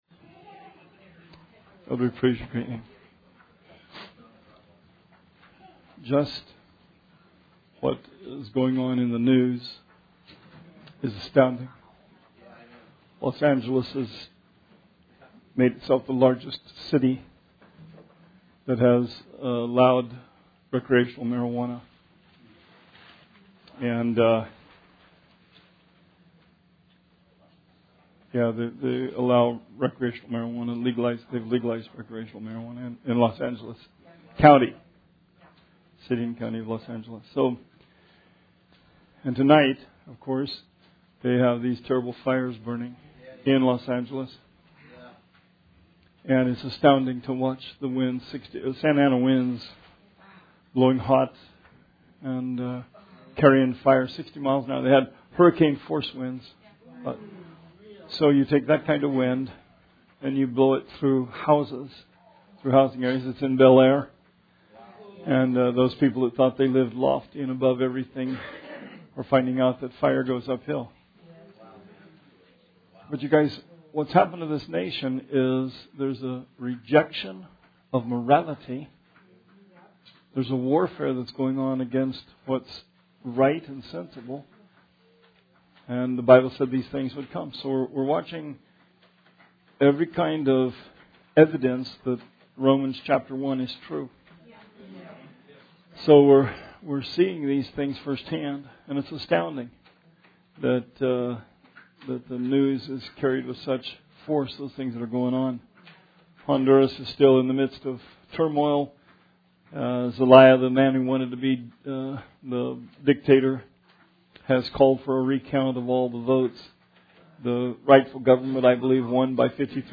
Bible Study 12/6/17